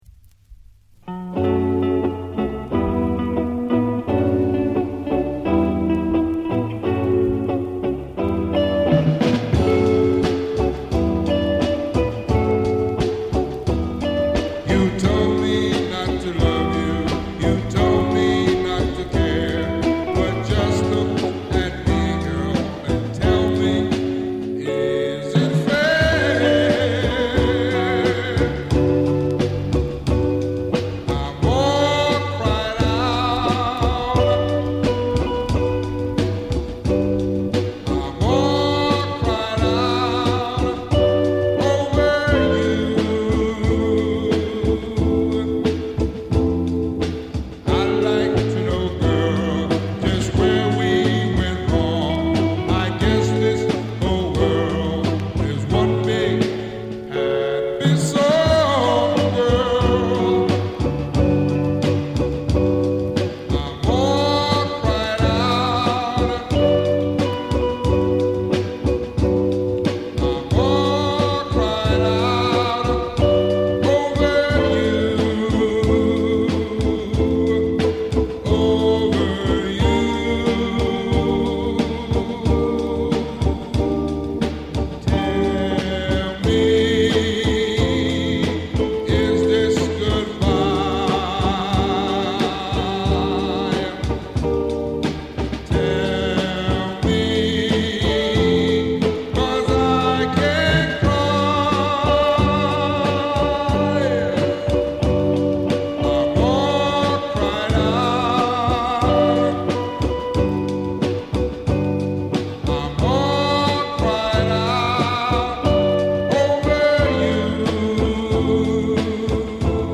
Soothing soulful goodness!!!